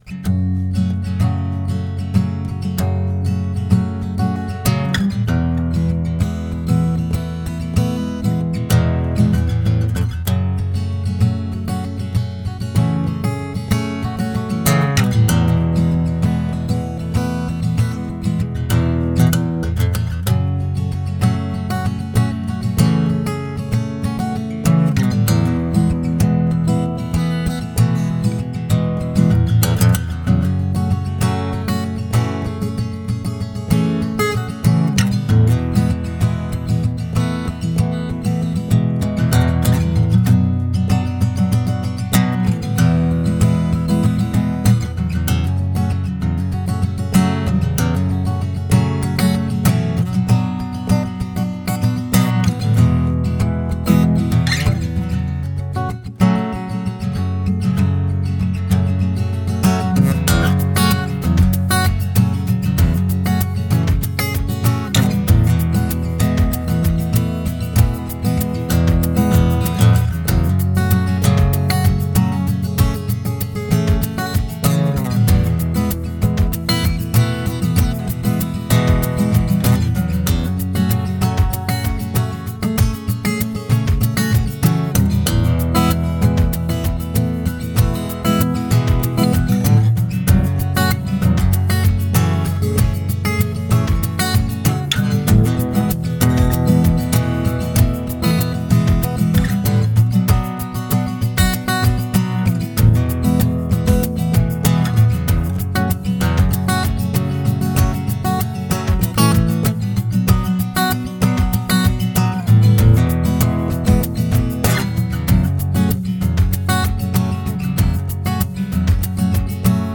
Fis moll